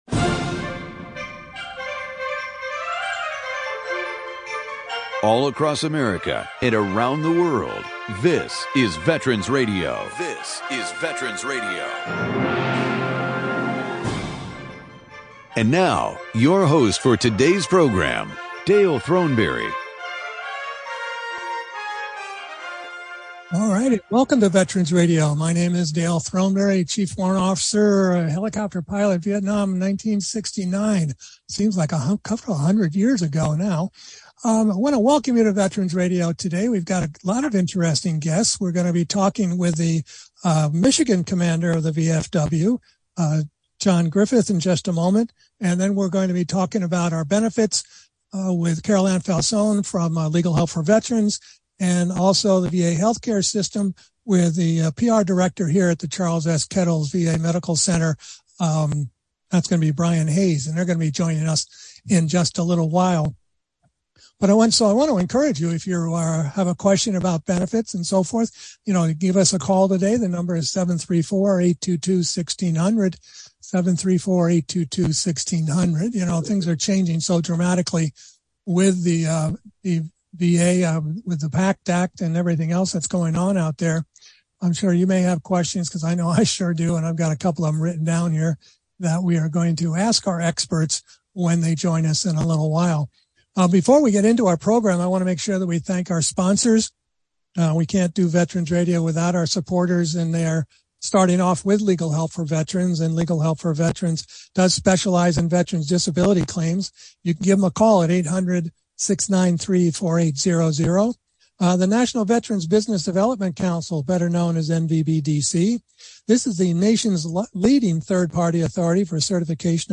Veterans Benefits – October 2022 This is our monthly veterans benefits program.